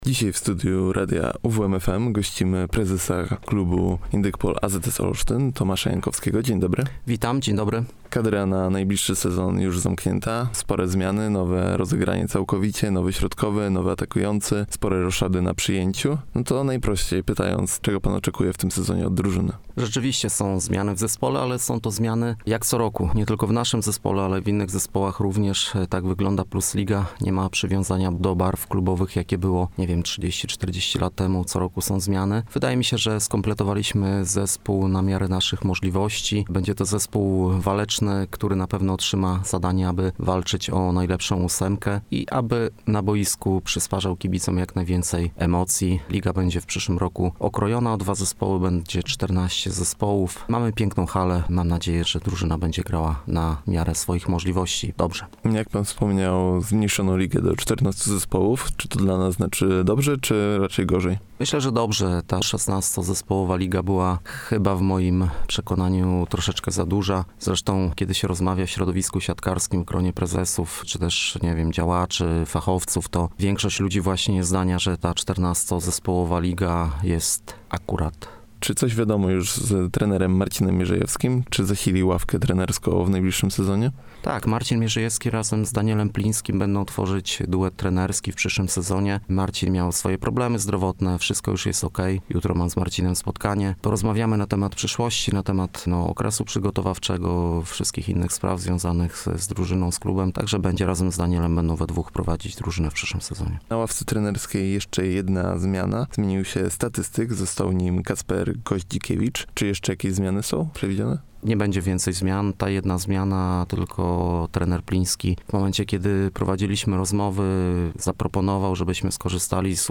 Indykpol AZS Olsztyn skompletował już całą kadrę na zbliżający się sezon 2025/2026.